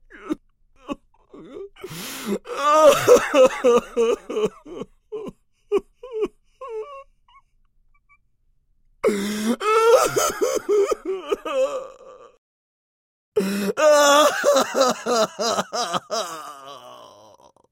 Звуки мужского плача
Мужчина печалится и рыдает